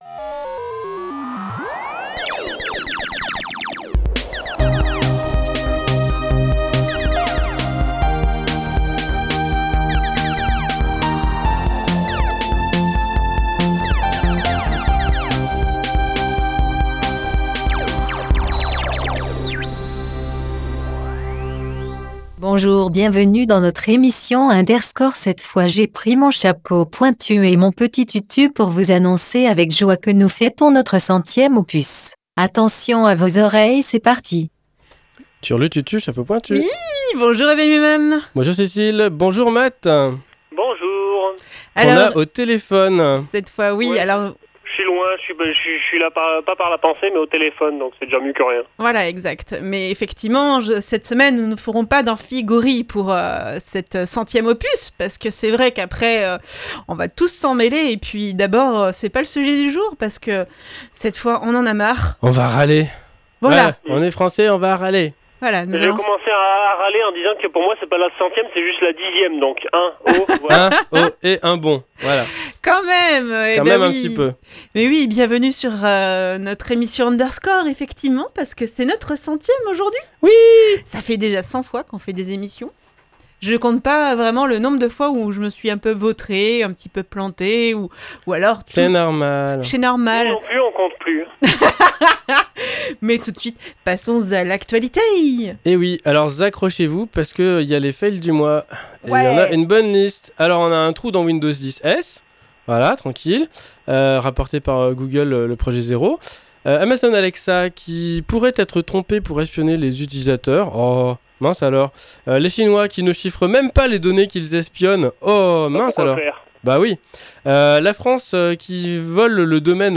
Les trucs qui m'énervent De l'actu, une pause chiptune, un sujet, l'agenda, et astrologeek !